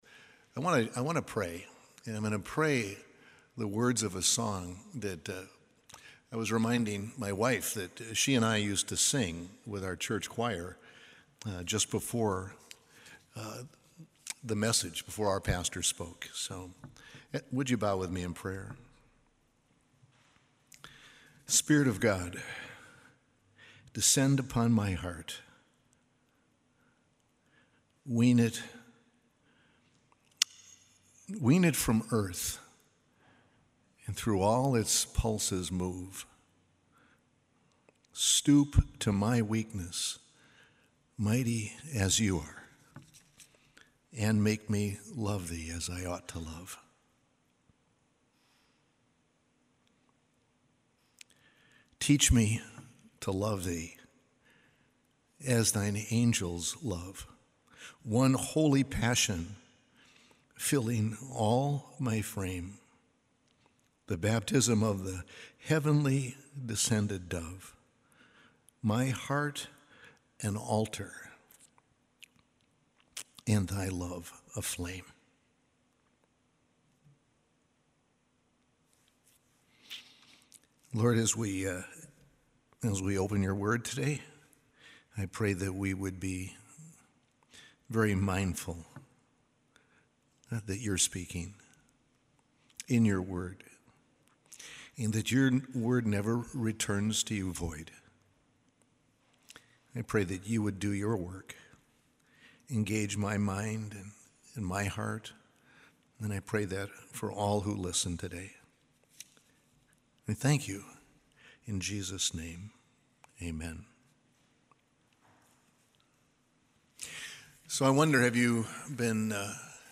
A message from the series "Galatians."